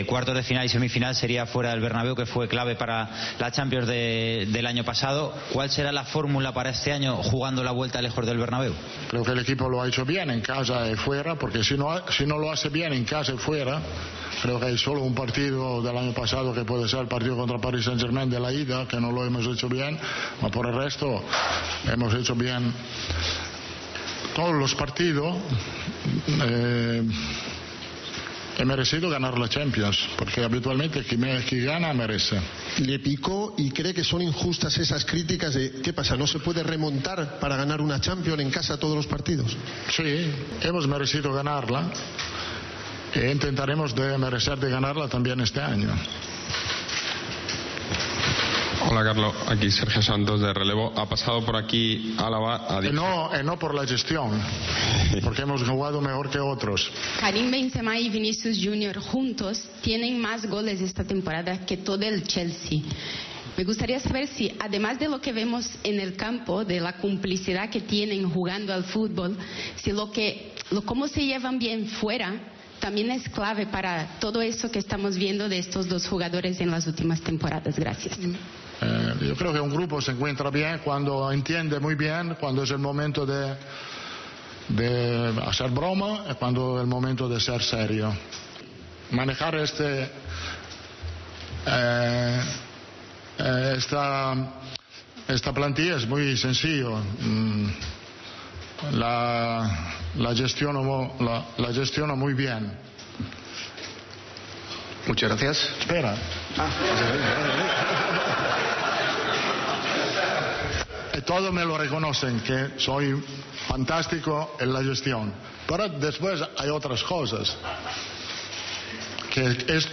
El entrenador del Real Madrid se reivindicó con un mensaje claro al término de la rueda de prensa. No quiso entrar en el tema Valverde y califica de "imparable" a Vinicius.
El entrenador del Real Madrid, Carlo Ancelotti, ha atendido a los medios de comunicación en la rueda de prensa previa al encuentro de ida de los cuartos de final de la Champions League ante el Chelsea.